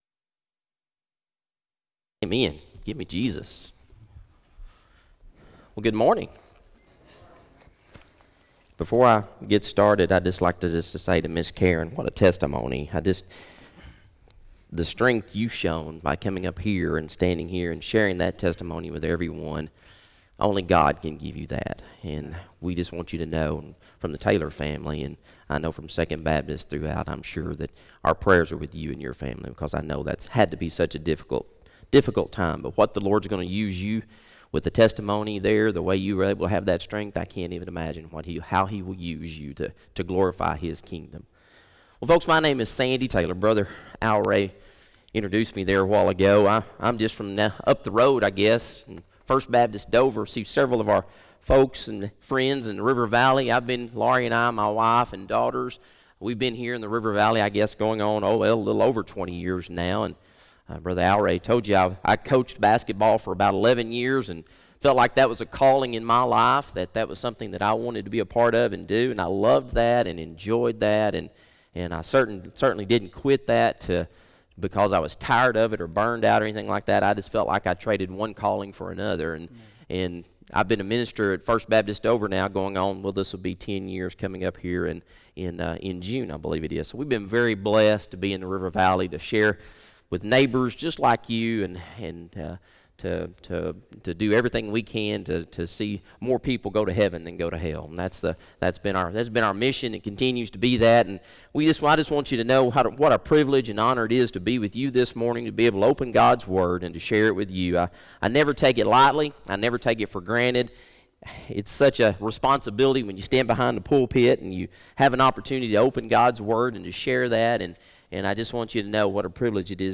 Morning Worship
by Office Manager | Mar 20, 2017 | Bulletin, Sermons | 0 comments